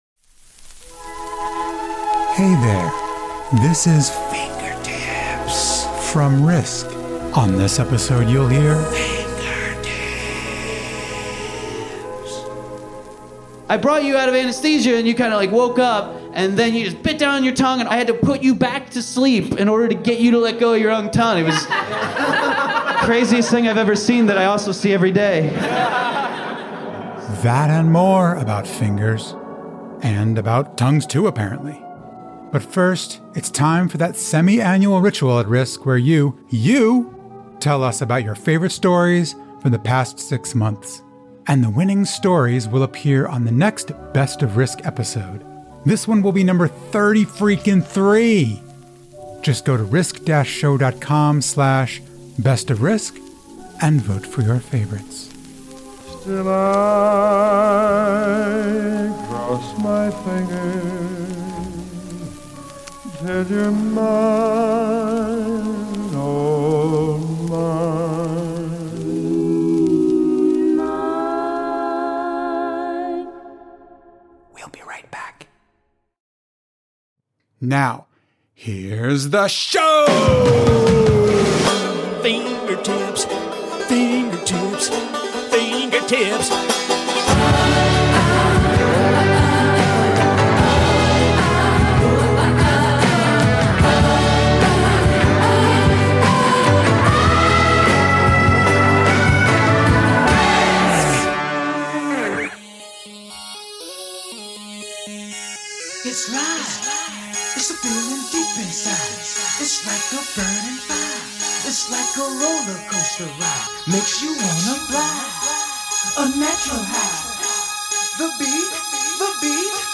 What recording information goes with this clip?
Live Story